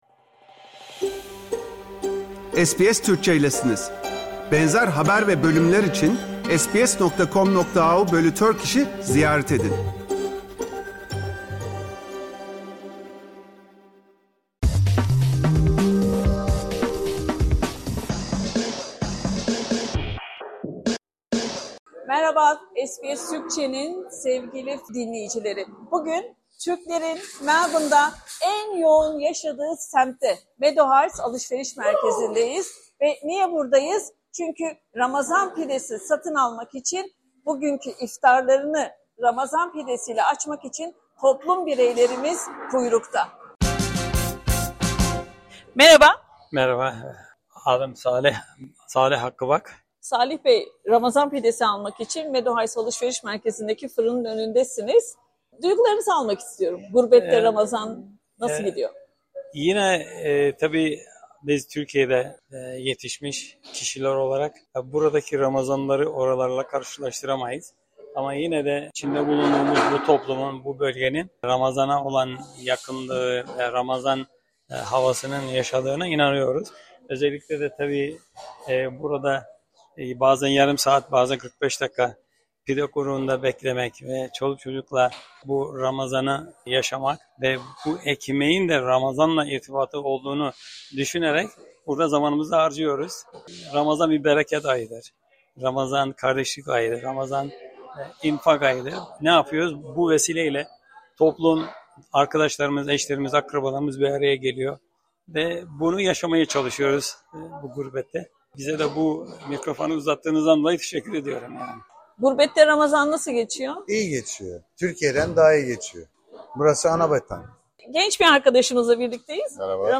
Ramazan denince akla gelen ilk şey, ekmeklerin şahı olarak da bilinen, sıcacık Ramazan pidesini sevmeyenimiz yoktur. Melbourne'de mis kokulu, sıcacık, çıtır çıtır Ramazan pidesi hasreti çekenler, Türk toplumunun yoğun olarak yaşadığı kuzey semtlerinden Meadow Heights'daki fırının önünden iftardan önce uzun kuyruklara giriyor SBS Türkçe kuyrukta pide bekleyenlere mikrofon uzattı.